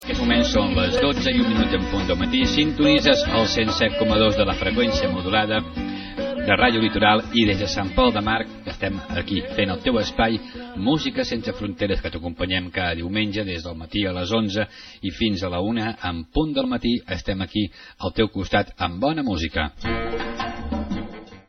Hora, identificació de l'emissora i del programa
FM